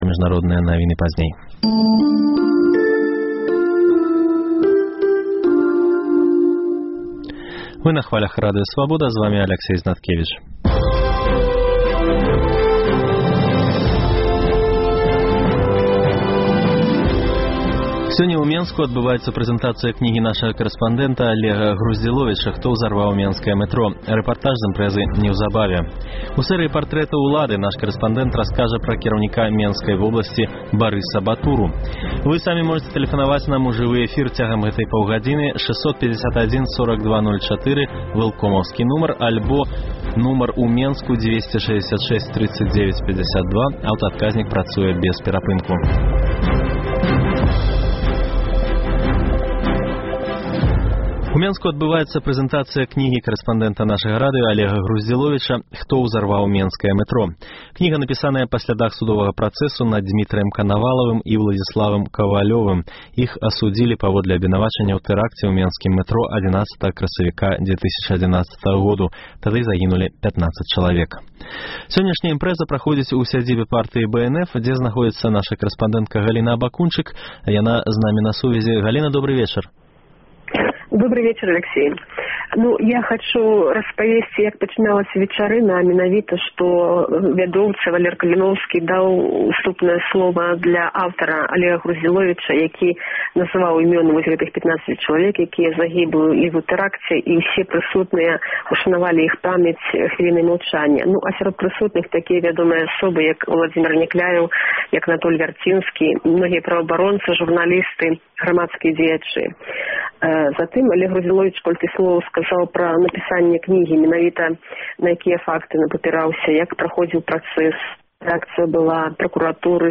Вы таксама пачуеце інтэрвію зь беларускай, якая жыве ў Стамбуле і якая брала ўдзел у цяперашніх пратэстах у Турцыі. У сэрыі партрэтаў улады наш карэспандэнт раскажа пра кіраўніка Менскай воласьці Барыса Батуру.